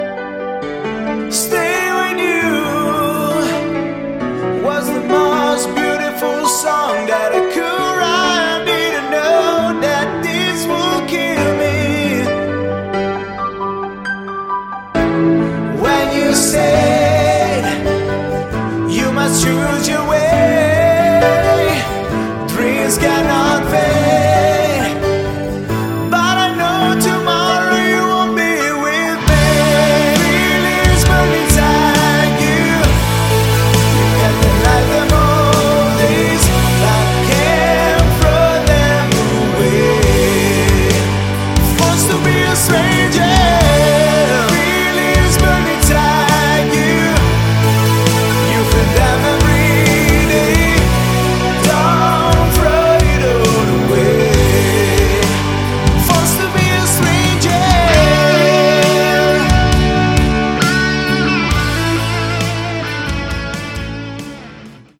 Category: Hard Rock
vocals
guitars
drums
bass
keyboards